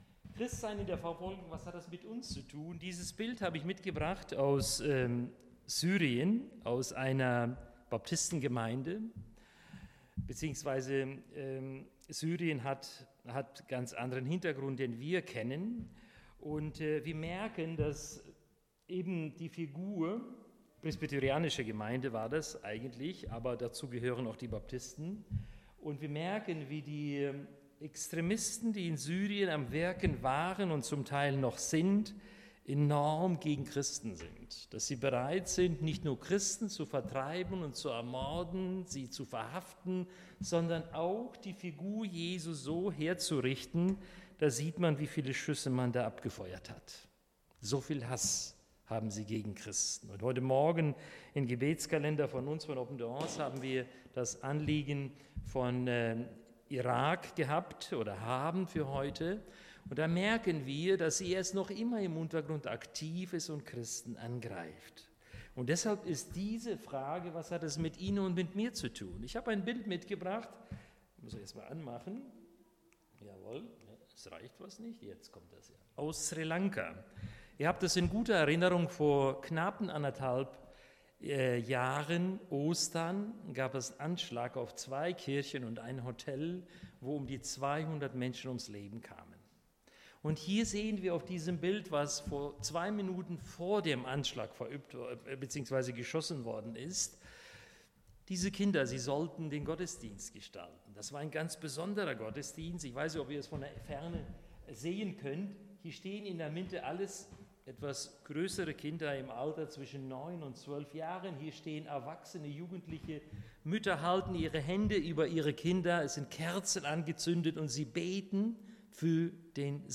31-33 Dienstart: Predigt Bible Text